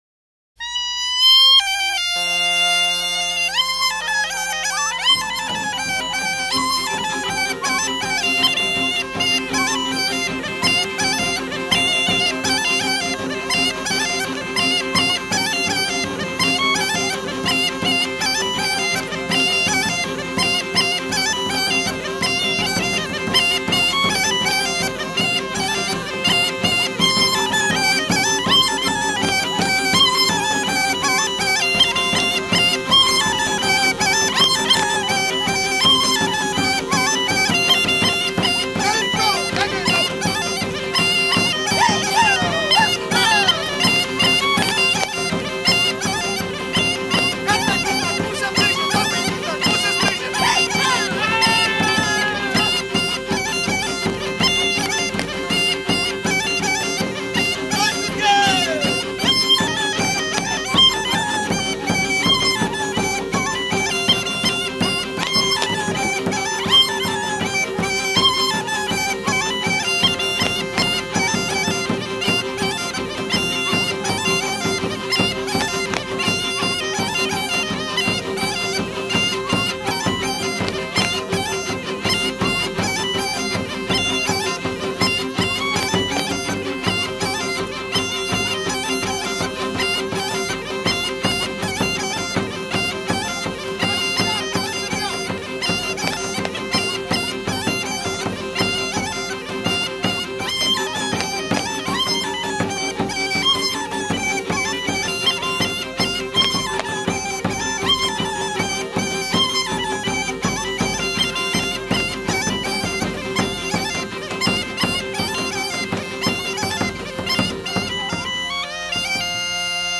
30-Crnorecje-Kolo-Gajdasko.mp3